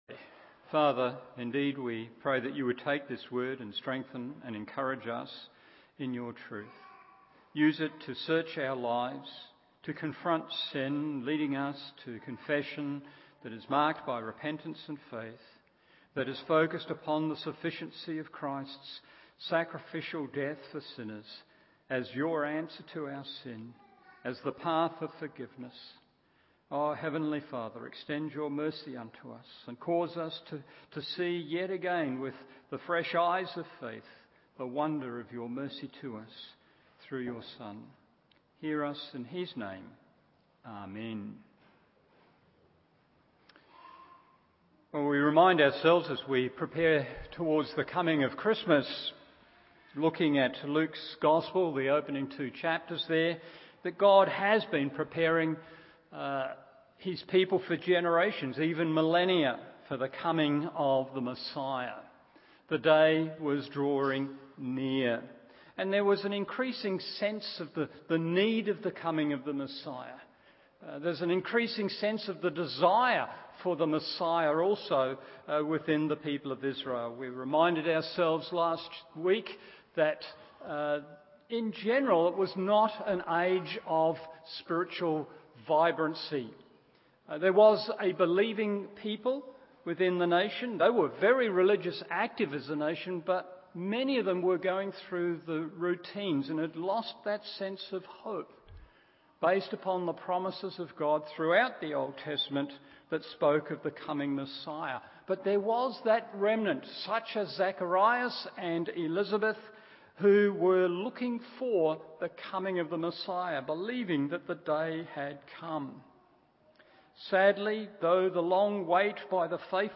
Evening Service Luke 1:26-38 1.